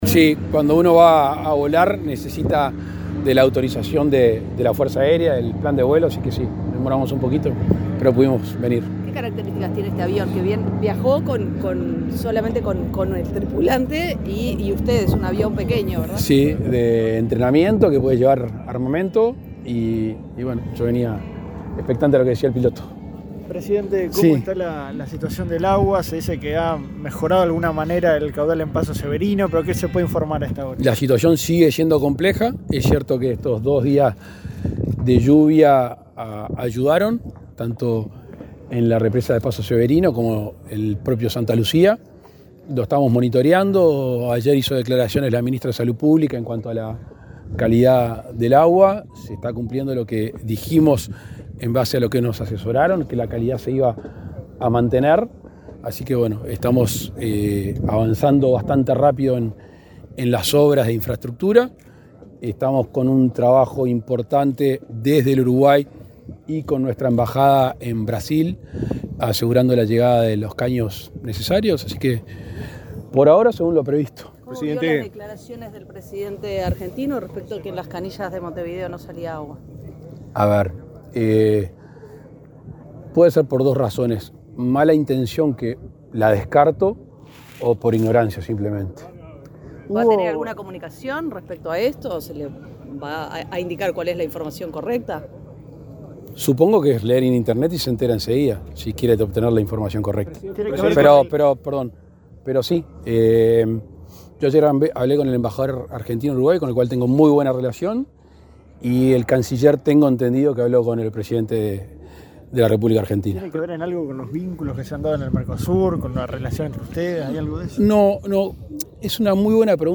Declaraciones del presidente Lacalle Pou a la prensa
El presidente Luis Lacalle Pou encabezó, este lunes 10 en Durazno, el acto por el 83.° aniversario de la Brigada Aérea II. Luego dialogó con la prensa